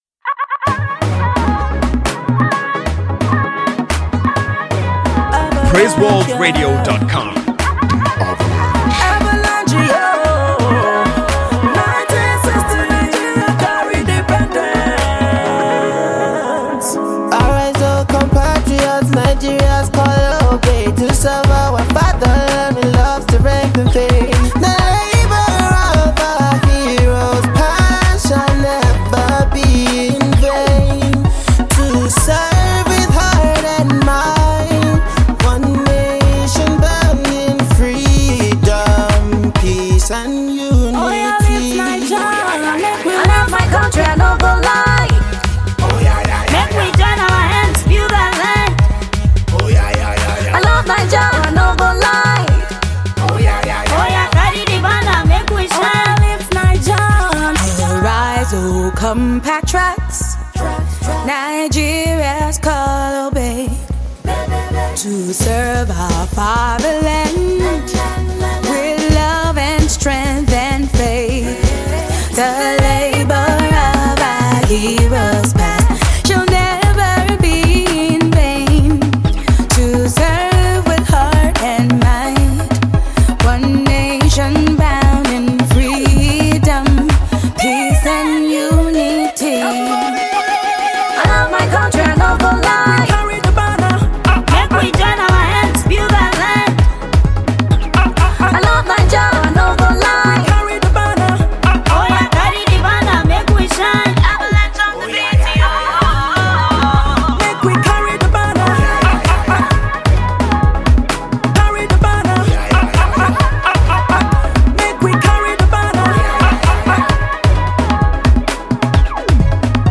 Official choir
Remix